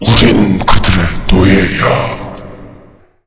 zombi_coming_2.wav